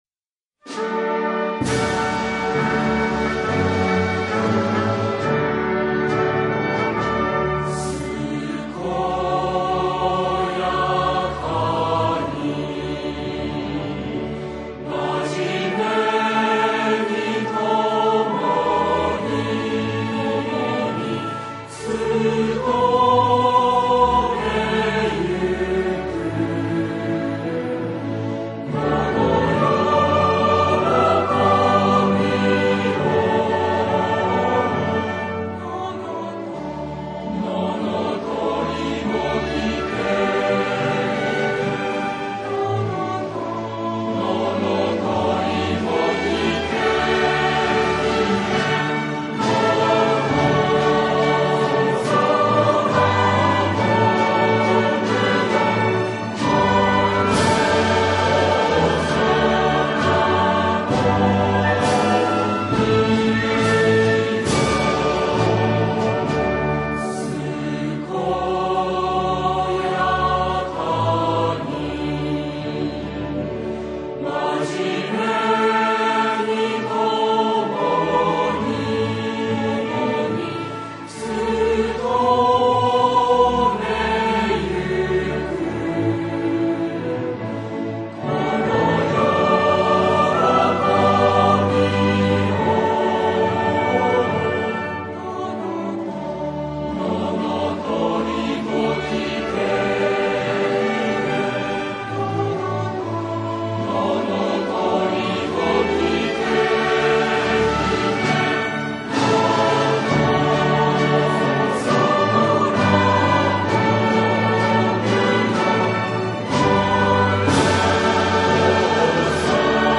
演奏：明星大学吹奏楽団 明星大学室内合奏部 合唱：明星大学混声合唱部